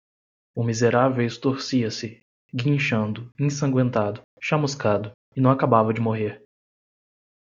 Pronounced as (IPA)
/mi.zeˈɾa.vew/